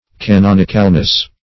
canonicalness - definition of canonicalness - synonyms, pronunciation, spelling from Free Dictionary
Search Result for " canonicalness" : The Collaborative International Dictionary of English v.0.48: Canonicalness \Ca*non"ic*al*ness\, n. The quality of being canonical; canonicity.
canonicalness.mp3